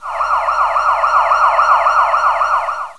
Index of /Sirens